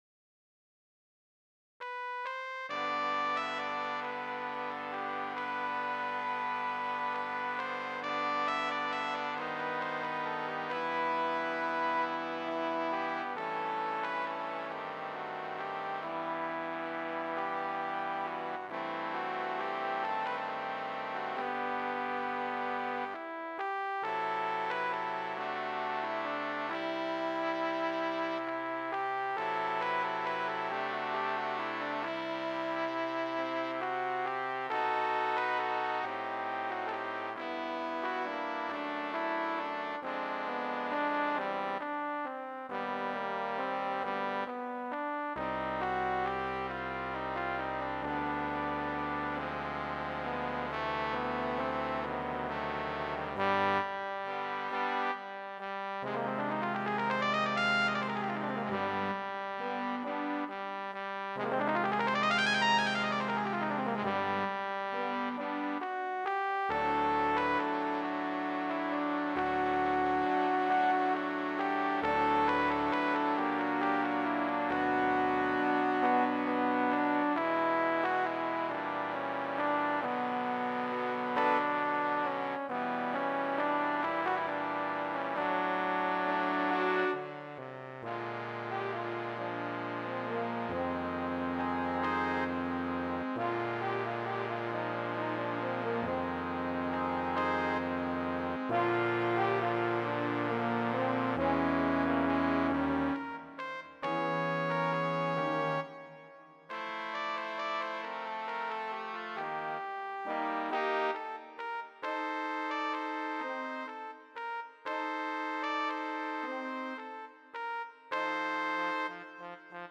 symphonic suite